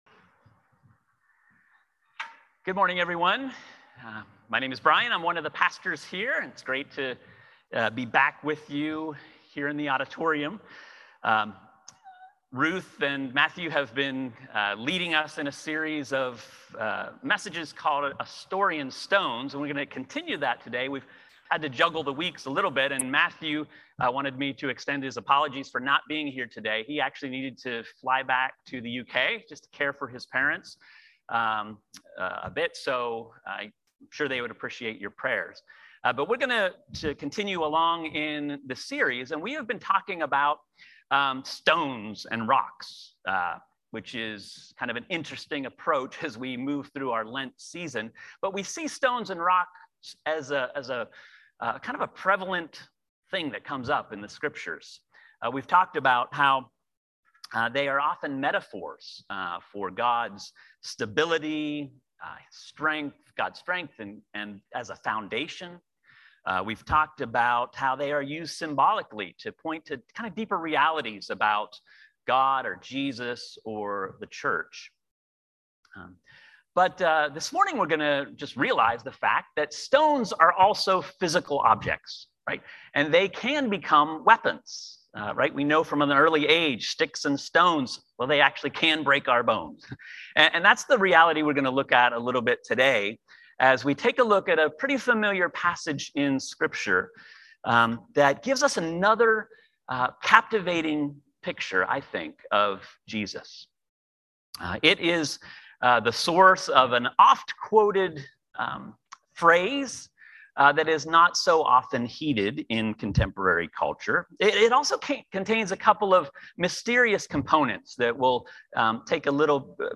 A message from the series "Winter Kindness." This week we look at our motivations for being kind, and examples from Jesus found in the gospels.